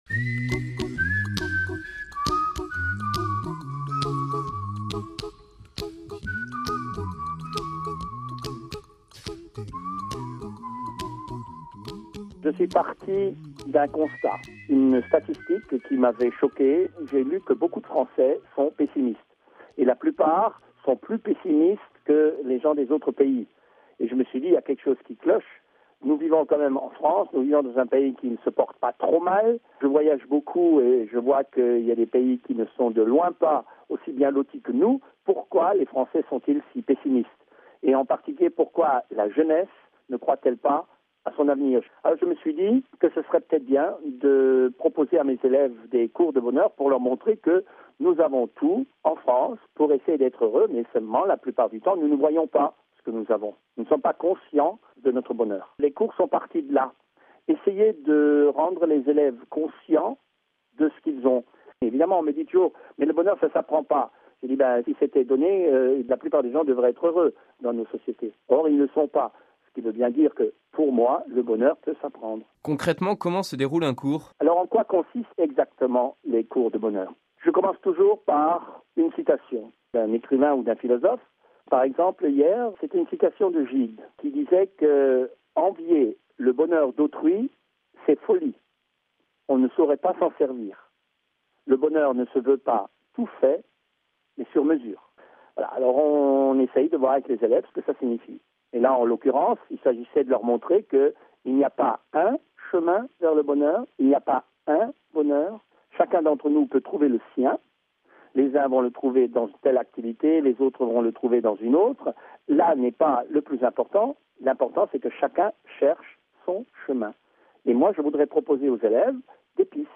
Il enseigne désormais le bonheur pour montrer aux jeunes qu’en France ils ont tout pour être heureux. Il est interrogé